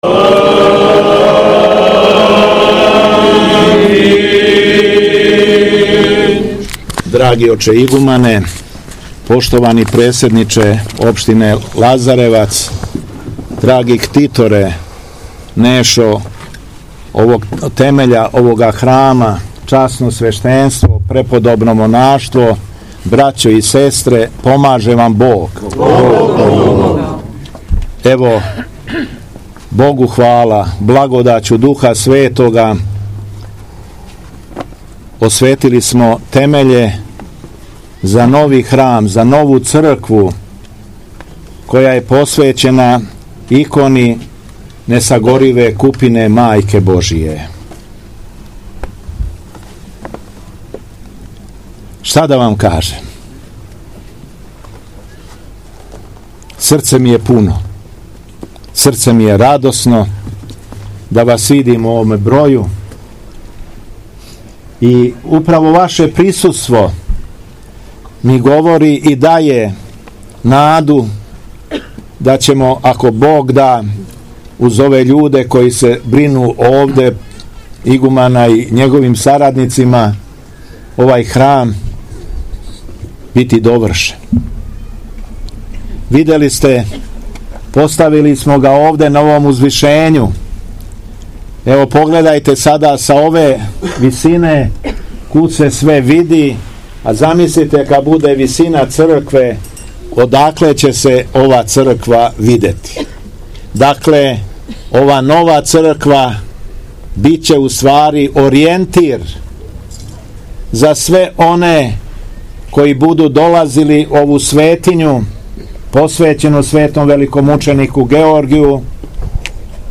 Беседа Његовог Високопреосвештенства Митрополита шумадијског г. Јована
У уторак 16. септембра 2025. године Његово Високопреосвештенство Митрополит шумадијски Господин Јован, у касним поподневним часовима, освештао је темеље и звона новог манастирског храма посвећеног чудотворној икони Пресвете Богородице “Купина несагорива” у селу Ћелије лајковачке.